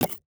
UIMvmt_Menu_Slide_Next_Page_Close 02.wav